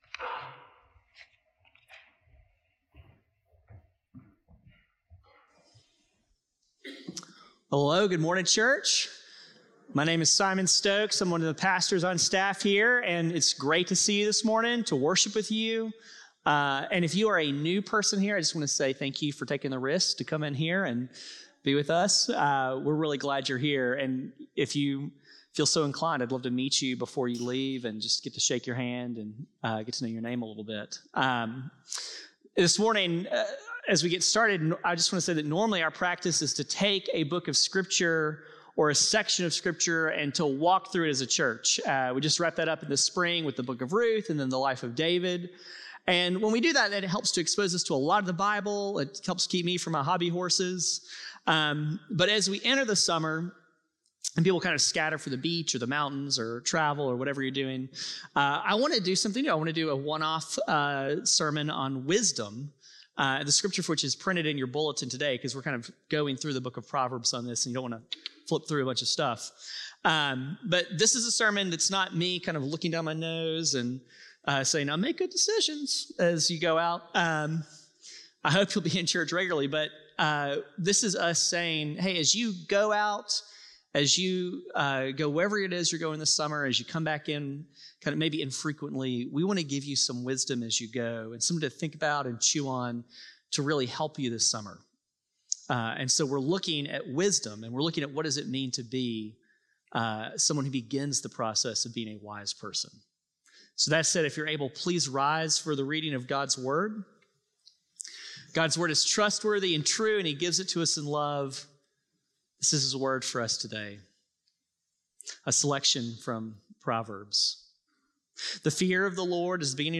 CGS-Service-6-1-25-Audio-Podcast.mp3